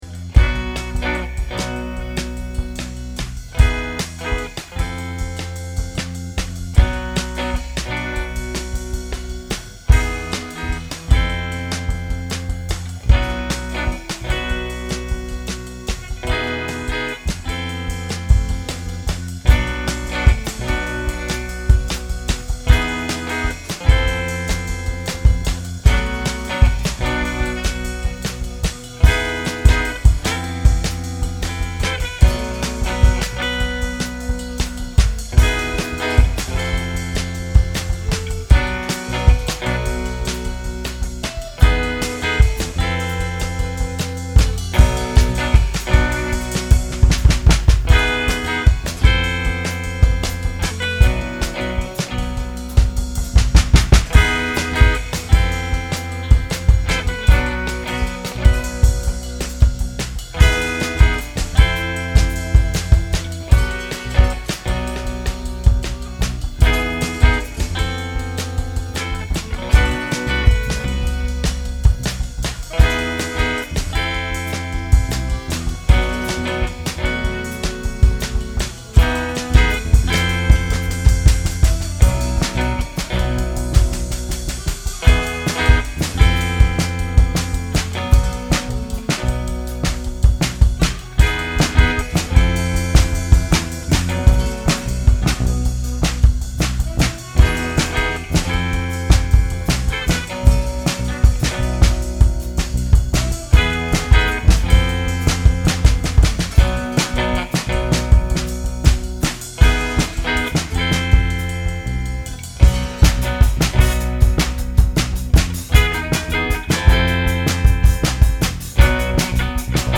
05JazzyInterlude.mp3